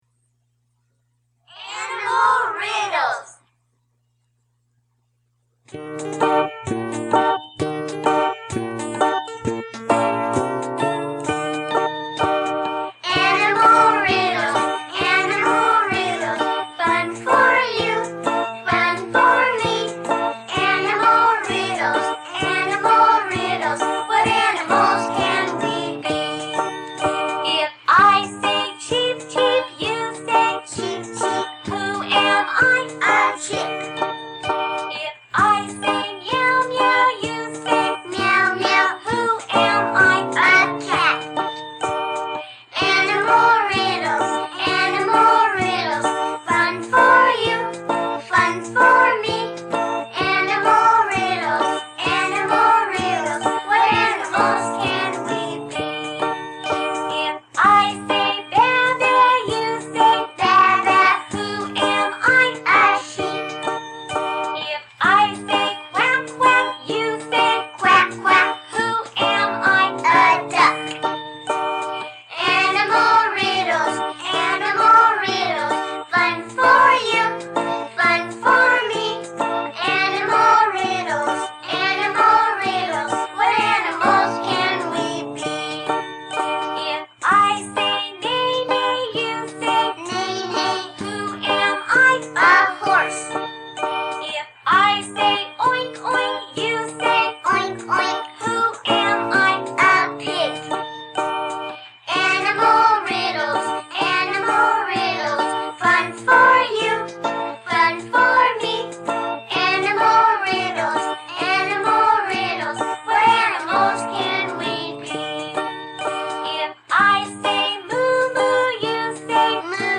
These are children songs for fun and learning.